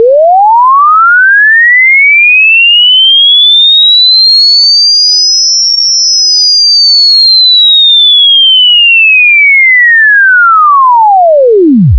Dans le second, on en entend un son pure dont la fréquence monte d’abord, puis redescend assez bas ensuite.
C’est un son d’une durée de 12 secondes, dont la fréquence commence à 440 Hz et dont la fréquence augmente progressivement jusqu’à atteindre 5720 Hz.
On commence à entendre sa hauteur baisser dès 6 secondes, quand il est à environ 3000 Hz.